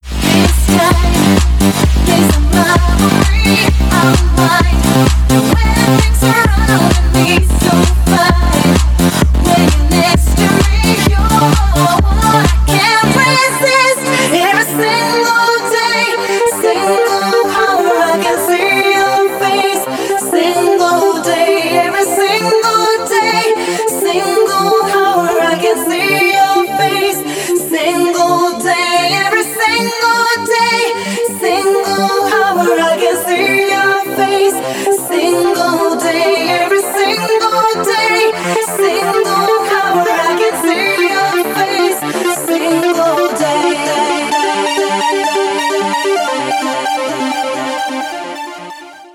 • Качество: 320, Stereo
dance
Electronic
club
house
electro